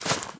latest / assets / minecraft / sounds / block / roots / step3.ogg
step3.ogg